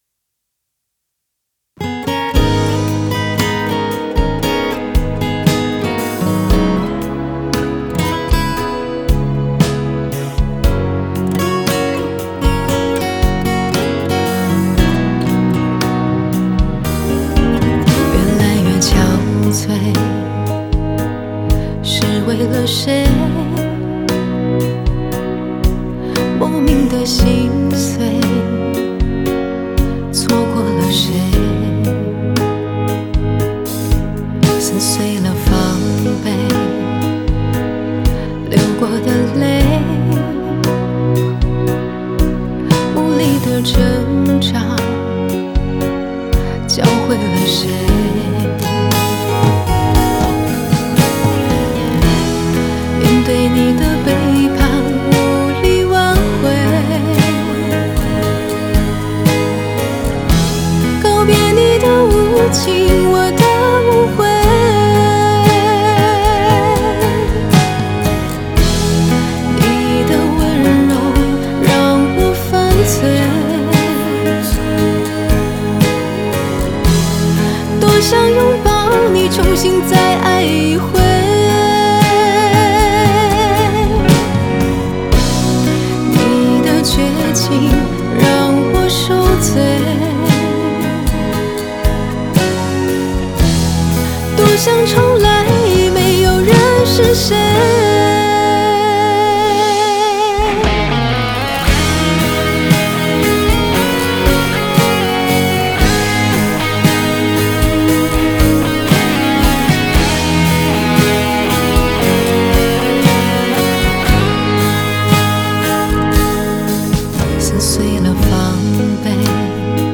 Ps：在线试听为压缩音质节选，体验无损音质请下载完整版
吉他Guitar